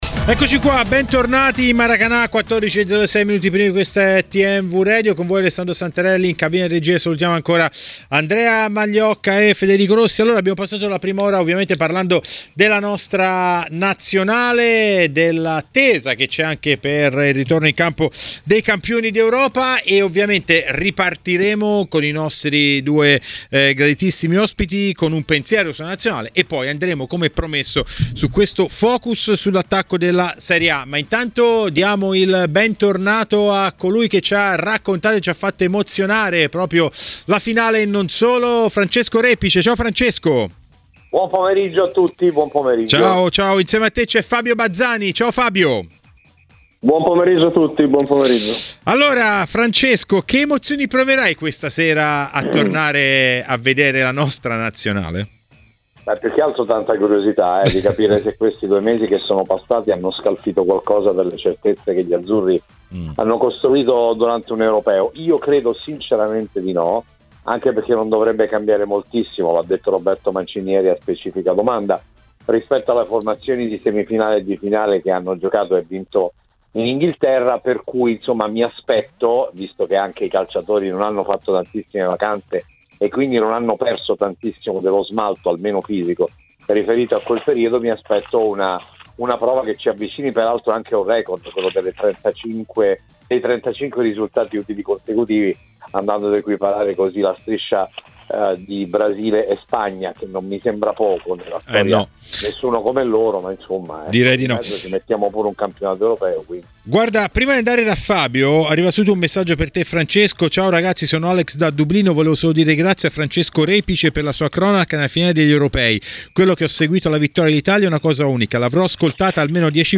A commentare le ultime sulla Serie A a Maracanà, nel pomeriggio di TMW Radio, è stato l'ex calciatore Fabio Bazzani.